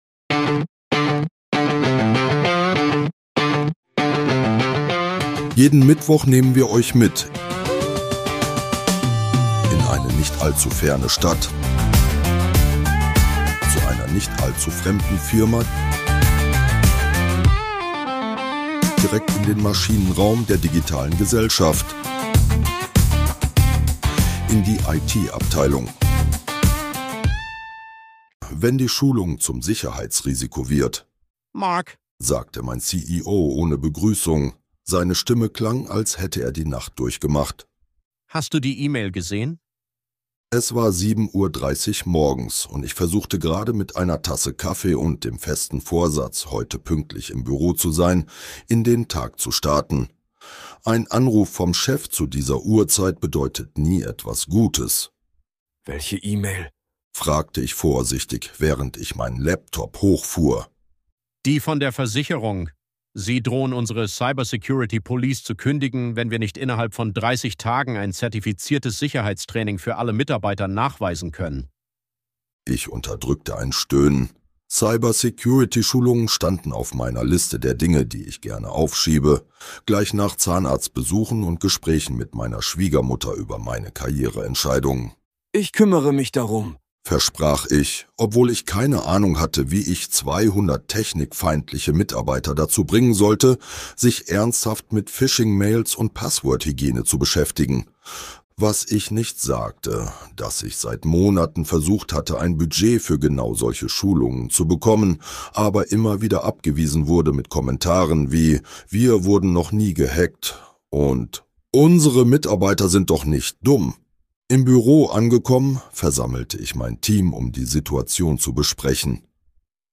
Dieser Podcast ist Comedy.